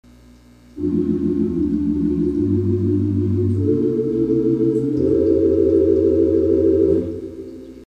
This is a smaller scale (pipe size or width for a given length) stopped wood rank with a sound like a miniature Tibia.